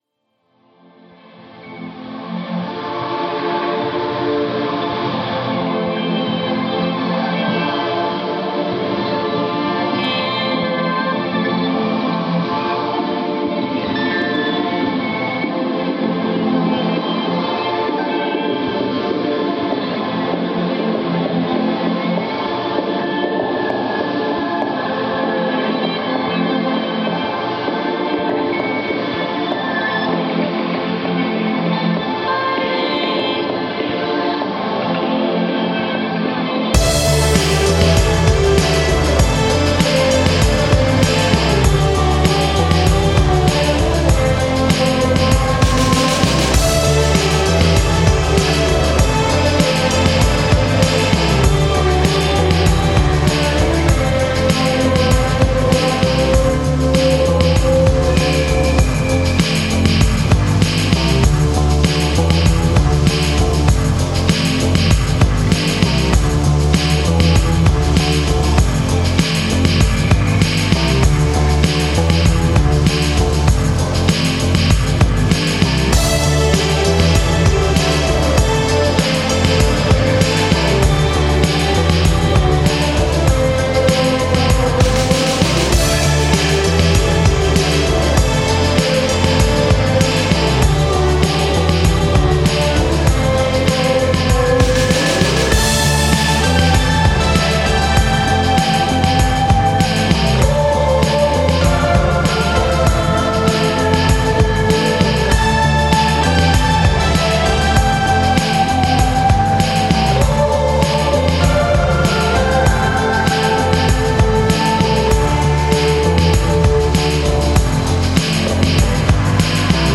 描述：流行乐|激越
Tag: 贝司 电吉他 键盘乐器 合成器